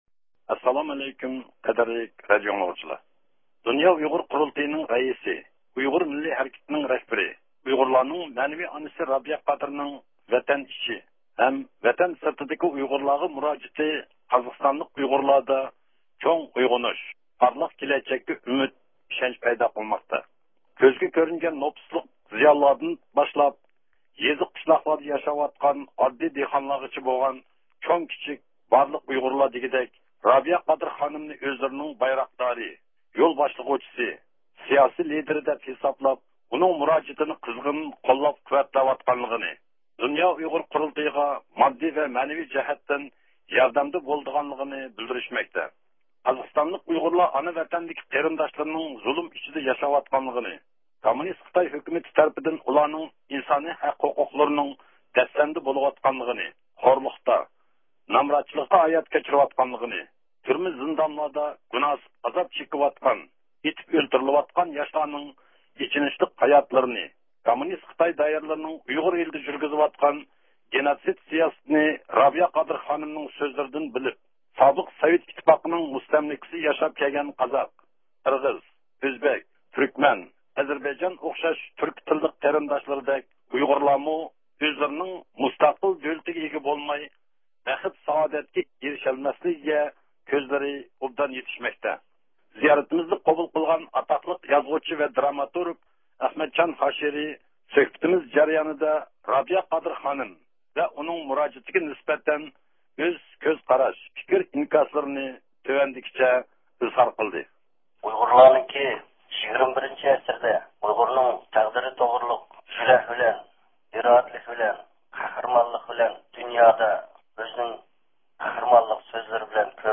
سۆھبەت زىيارىتىنىڭ تەپسىلاتىنى ئاڭلايسىز.